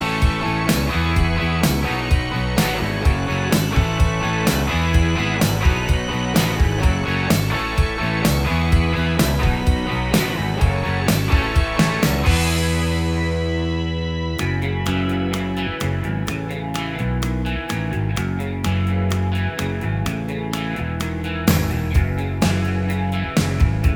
No Lead Guitar Rock 3:58 Buy £1.50